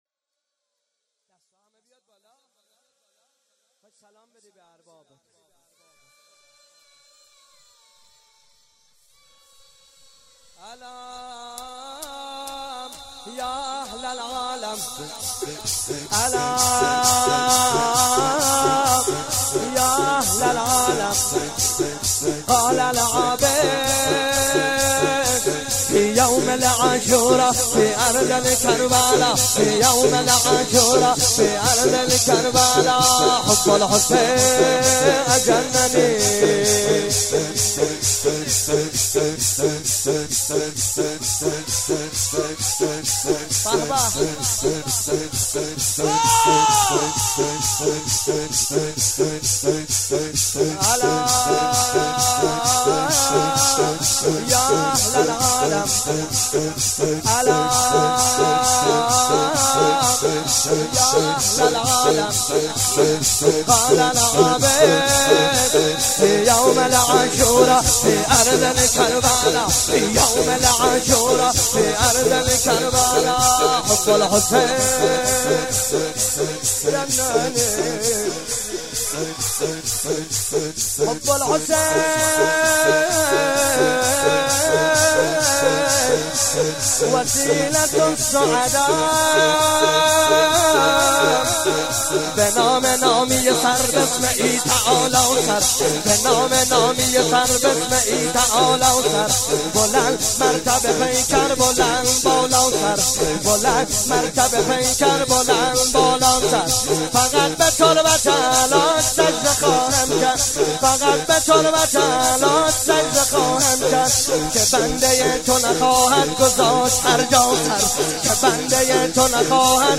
شب هفتم رمضان95، حاج محمدرضا طاهری
زمینه، روضه، مناجات